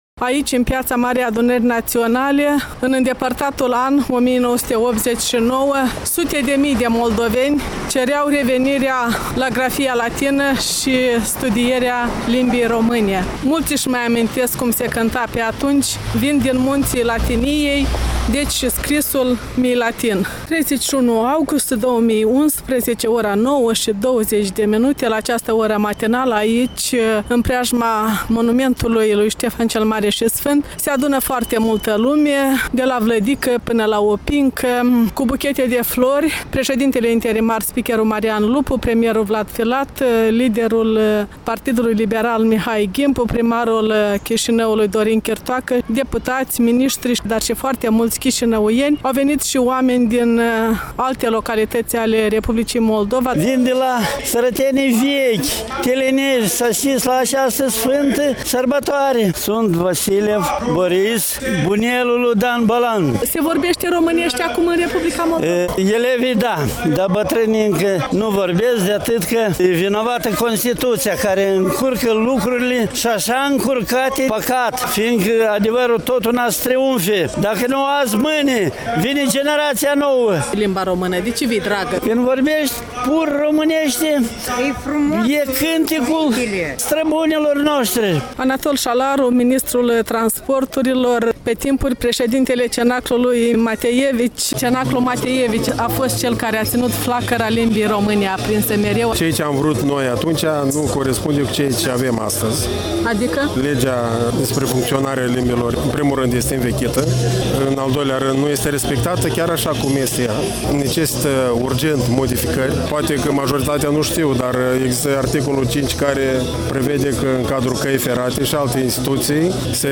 Cu microfonul Europei Libere la manifestaţiile de Ziua limbii